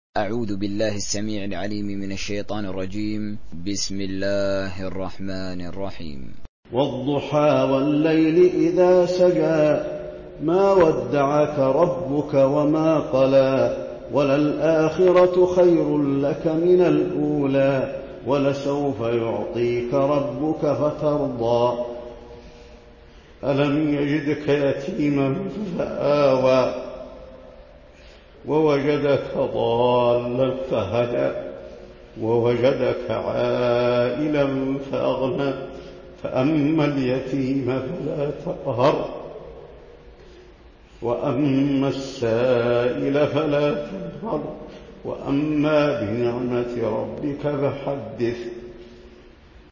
تحميل سورة الضحى mp3 بصوت حسين آل الشيخ تراويح برواية حفص عن عاصم, تحميل استماع القرآن الكريم على الجوال mp3 كاملا بروابط مباشرة وسريعة
تحميل سورة الضحى حسين آل الشيخ تراويح